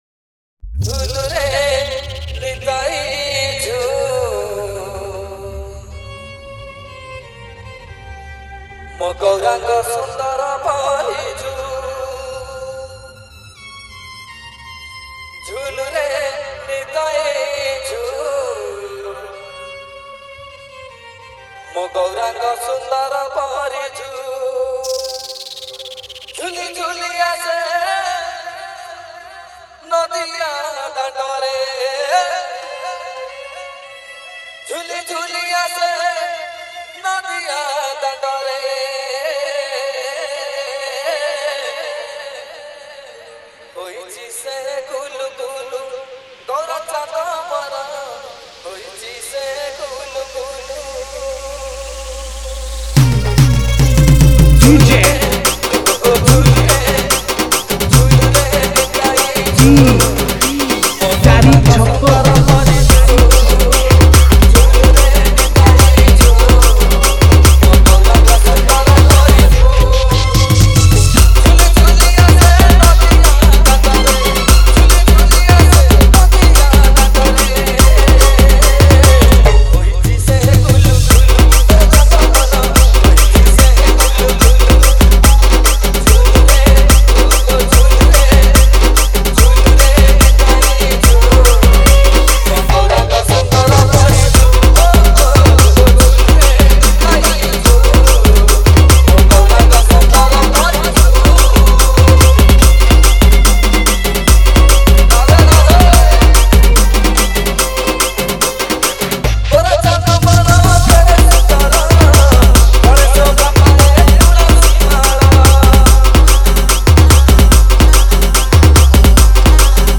Bhajan Dj Remix